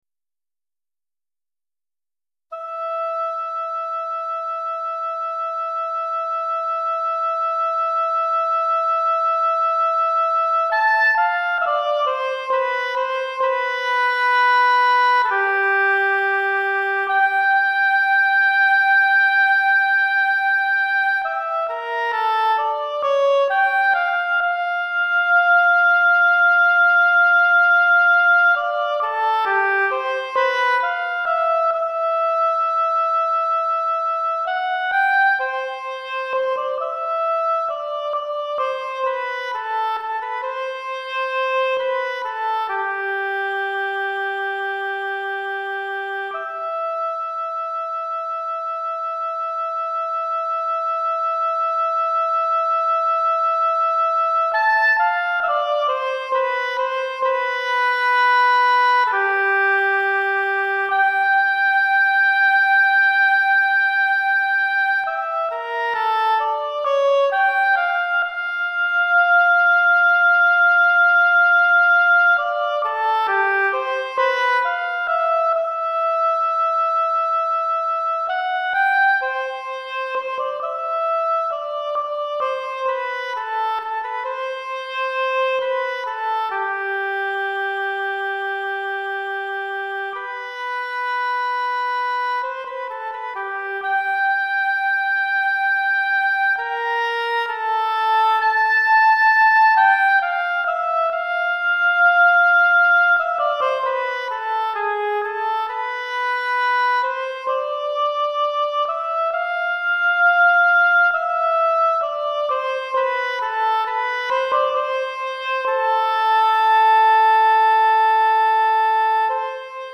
Hautbois Solo